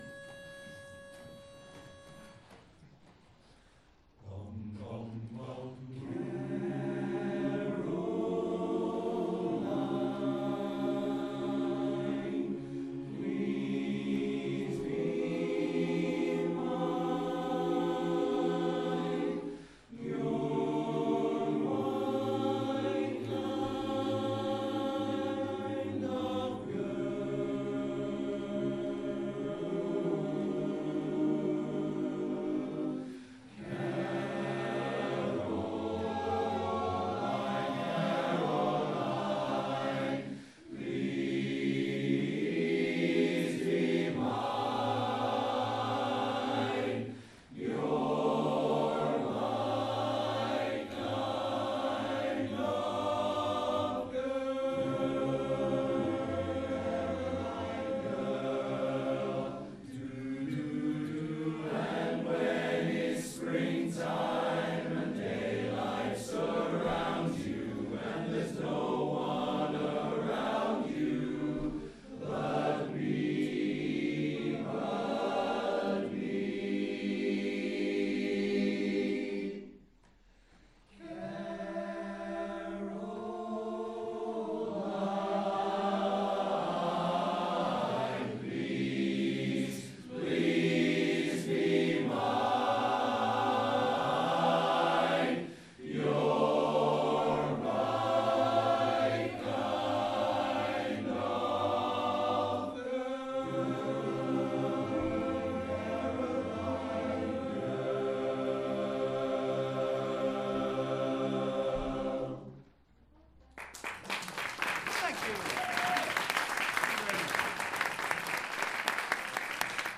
A Bit of Singing from the Barbershop …
This may not be poetry … well actually, powerful and spine tingling vocal harmonies should be considered as poetry, when I think about it.
In the meantime, I hope you enjoy these fairly rudimentary recordings of that first informal performance …